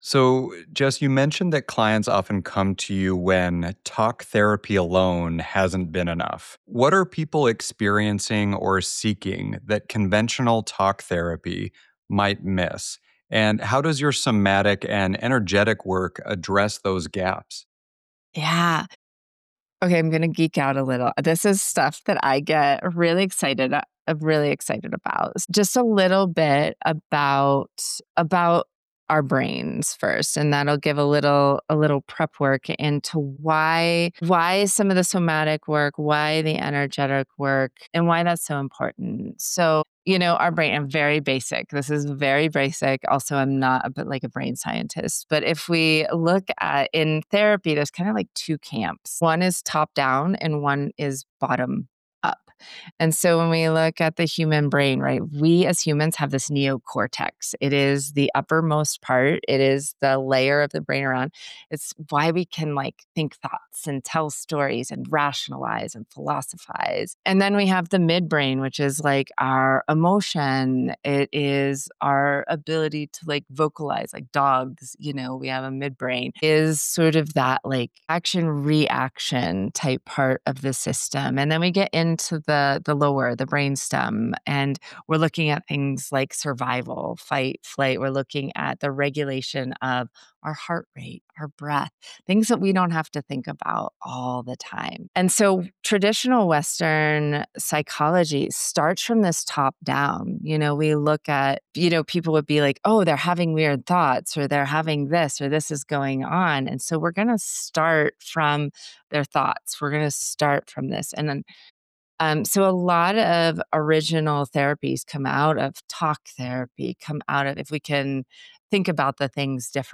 A profound conversation about trauma healing, somatic wisdom, and supporting "big spirits navigating life on Earth" through liminal spaces and threshold moments.